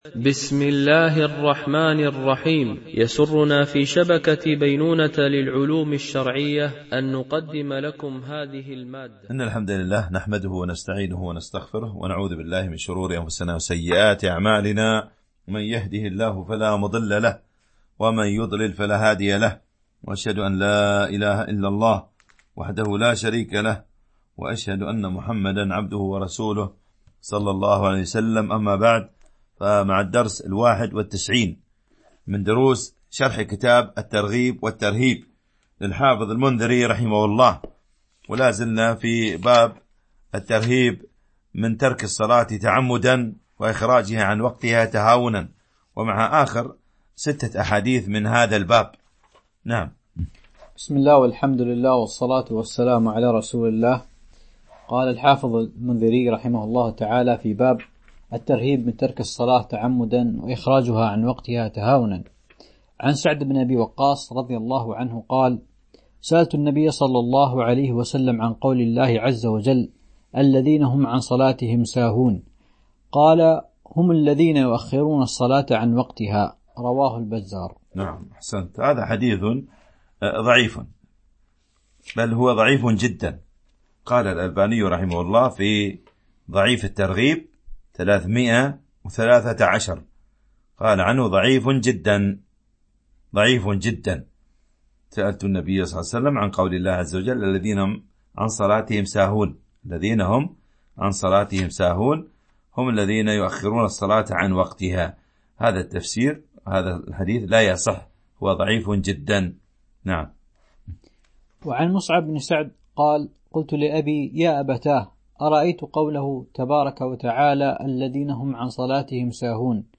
التنسيق: MP3 Mono 22kHz 32Kbps (CBR)